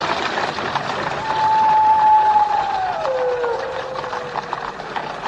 wolves howling.ogg
Original creative-commons licensed sounds for DJ's and music producers, recorded with high quality studio microphones.
[wolves-howling-sound-effect]_z8c.mp3